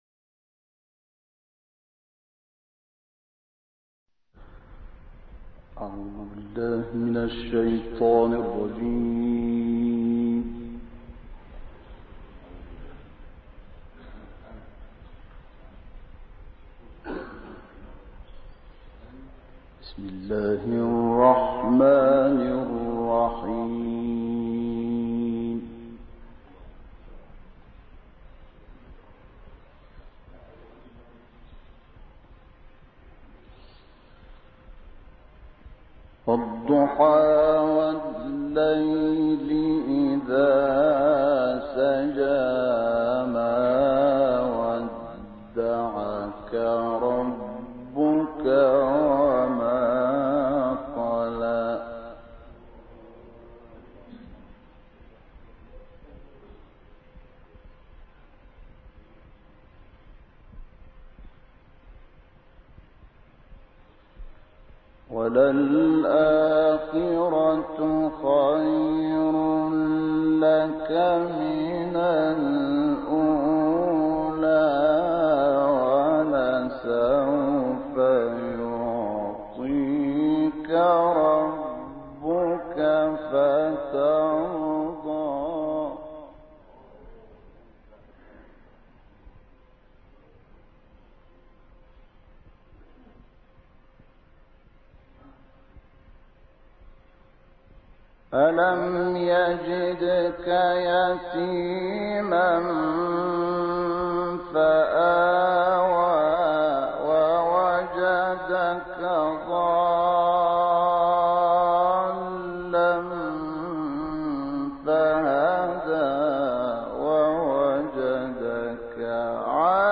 دانلود قرائت سوره های ضحی و عادیات - استاد شحات محمد انور